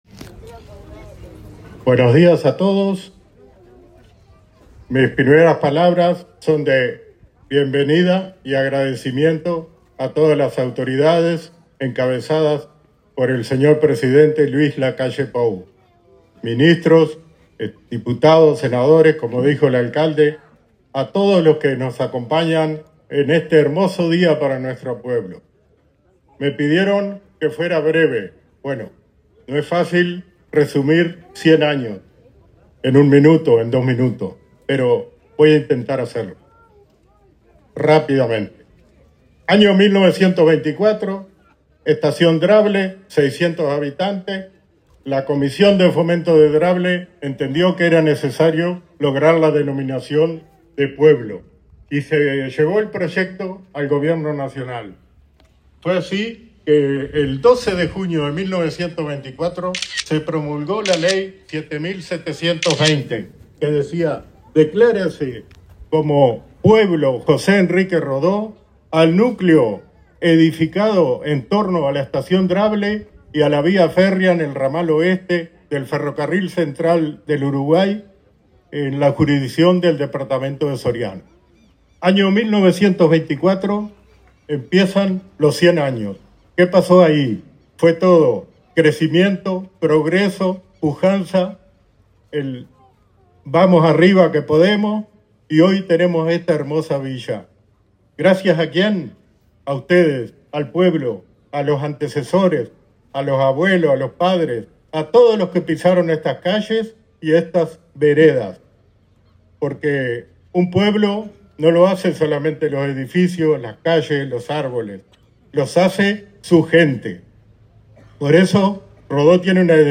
Se cumplió el acto protocolar de los 100 años de  la denominación de  la localidad de José E. Rodó.
Resena-100-anos-Rodo-1.mp3